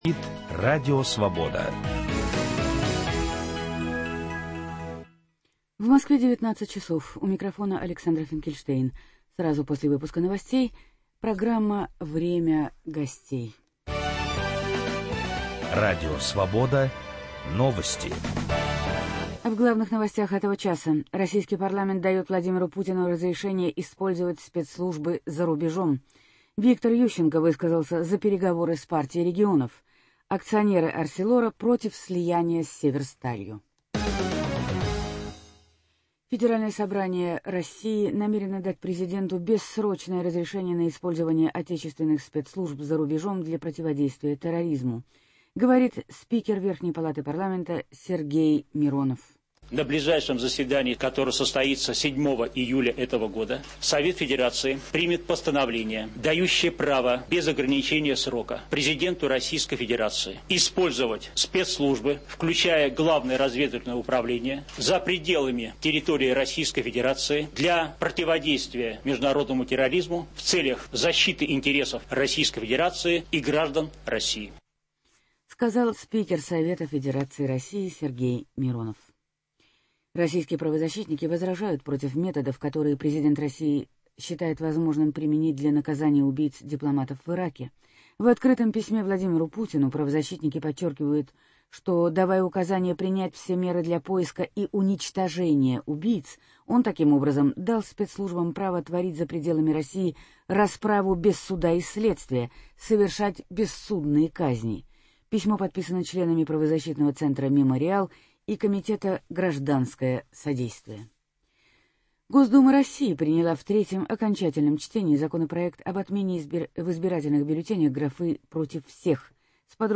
Что будет с украинской экономикой после формирования нового правительства? В программе участвует депутат Верховной Рады Украины, бывший министр экономики Сергей Терехин.